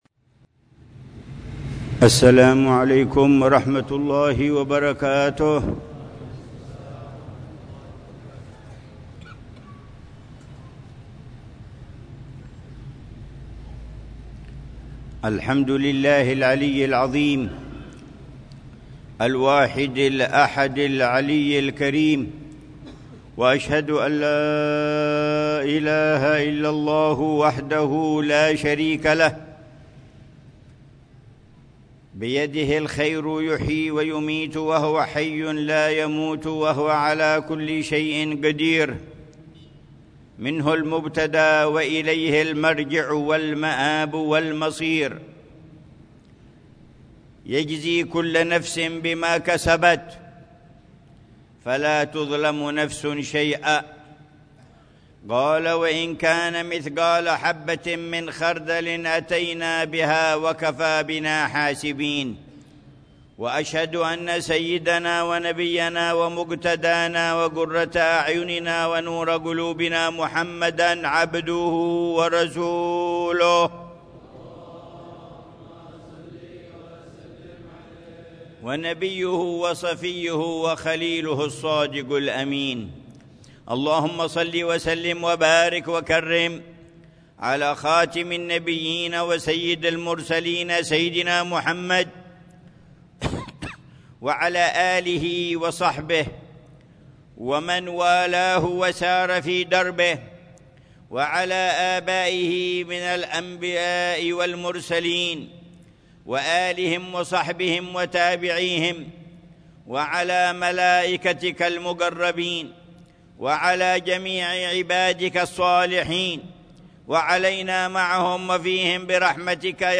خطبة الجمعة للعلامة الحبيب عمر بن محمد بن حفيظ في جامع الإيمان، بحارة الإيمان، عيديد، مدينة تريم، 14 جمادى الثانية 1447هـ بعنوان: